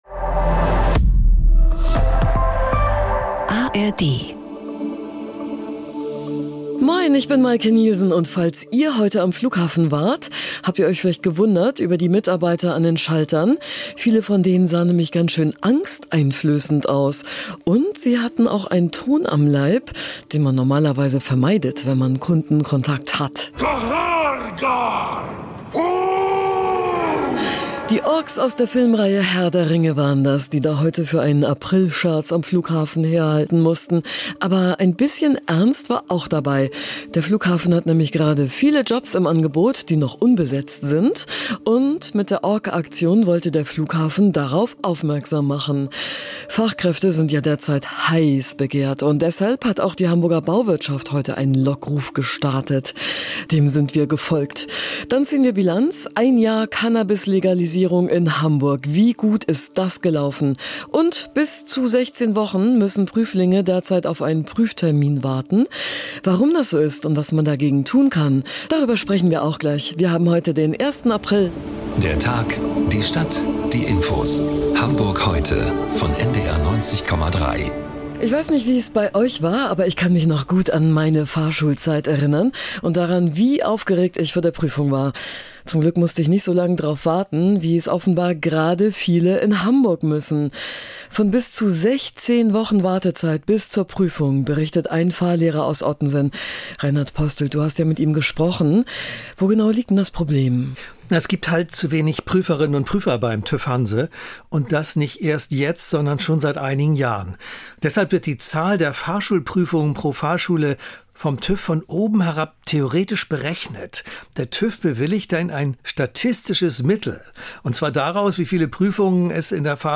Unsere Reporterinnen und Reporter sind für Sie sowohl nördlich als auch südlich der Elbe unterwegs interviewen Menschen aus Wirtschaft, Gesellschaft, Politik, Sport und Kultur.
„Hamburg Heute“ liefert Informationen aus erster Hand und gibt Antworten auf aktuelle Fragestellungen. … continue reading 503 에피소드 # NDR 90,3 # NDR 90 # Tägliche Nachrichten # Nachrichten # St Pauli